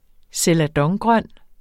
Udtale [ selaˈdʌŋ- ]